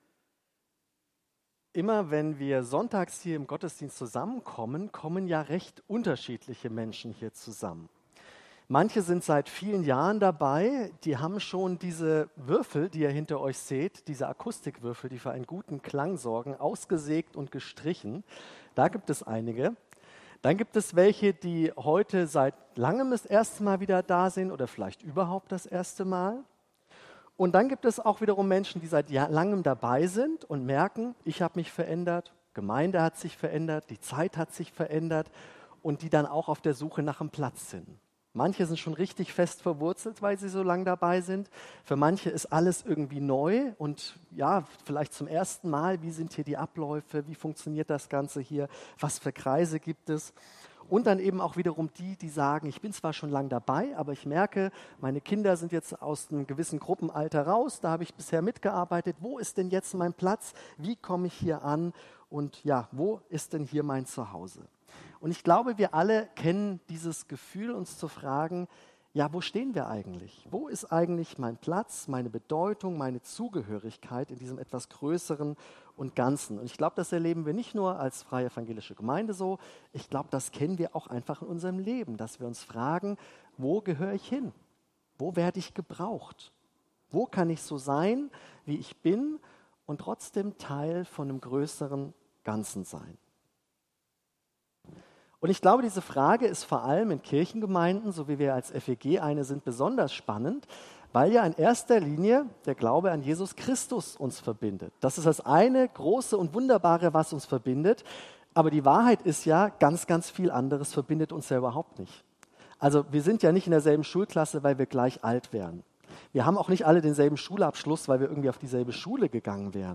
FeG Badschönborn >> Predigtpodcast